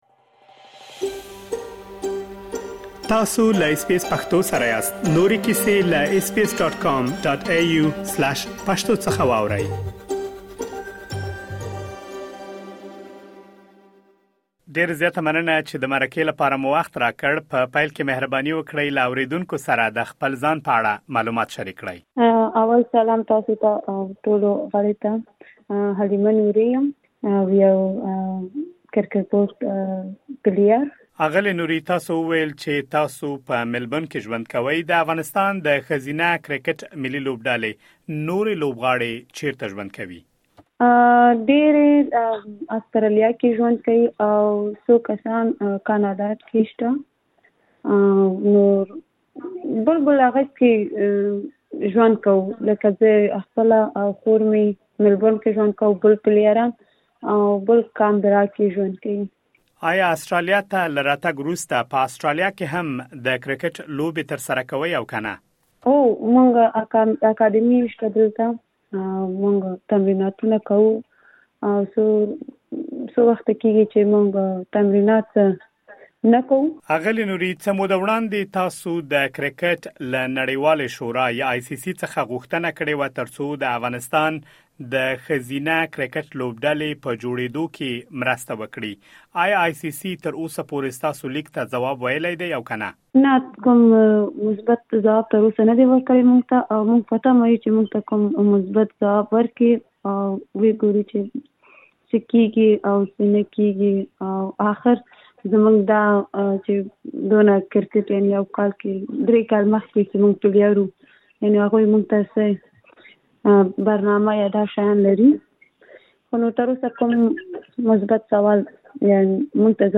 تاسو کولی شئ لا ډېر معلومات په ترسره شوې مرکې کې واورئ.